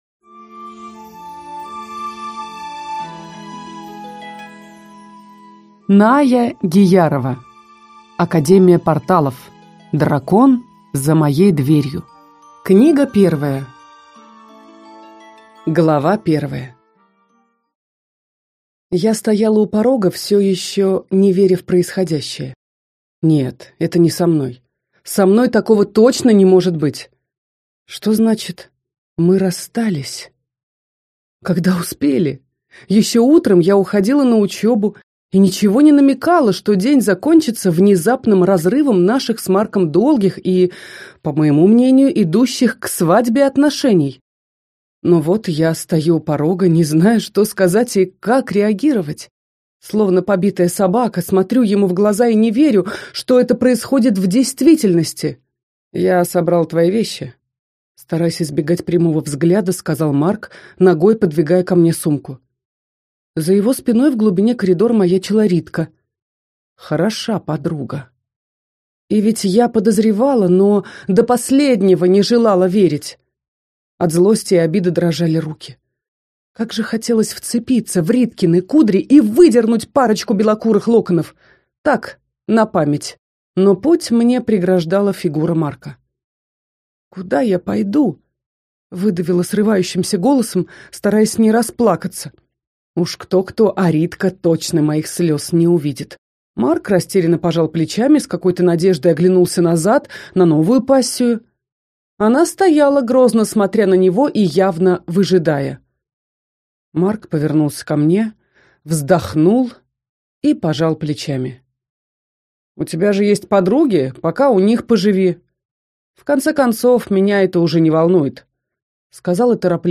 Аудиокнига Академия порталов. Дракон за моей дверью. Книга 1 | Библиотека аудиокниг